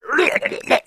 На этой странице собраны забавные звуки гномов: смех, шутки, шаги и другие загадочные шумы.